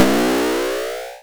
heal_b.wav